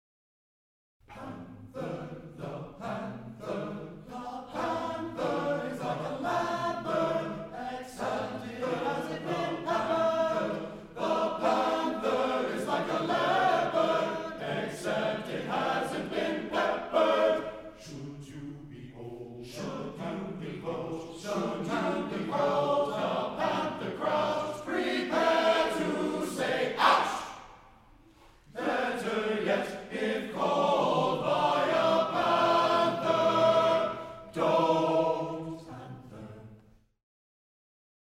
TTBB Chorus a cappella